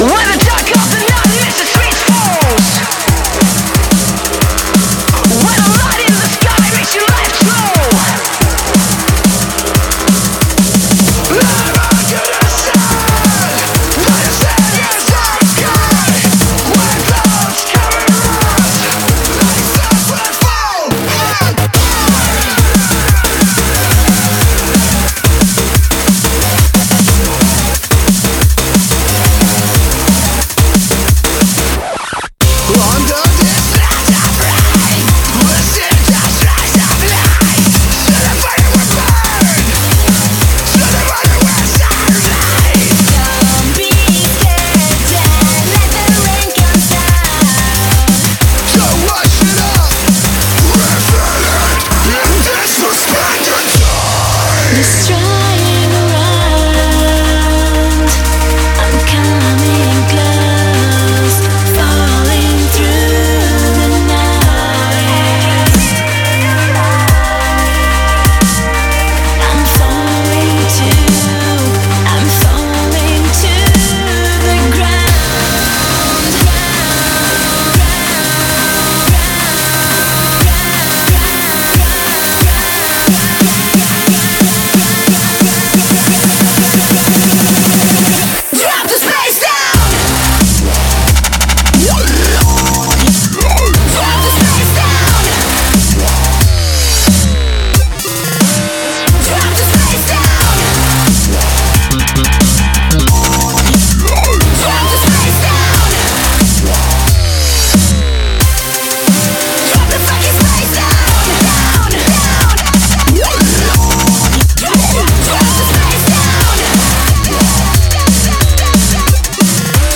BPM90-180
Audio QualityPerfect (High Quality)
Comments[DRUMSTEP]